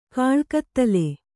♪ kāḷkattale